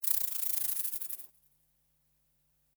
Serpiente de cascabel
Sonidos: Animales Reptiles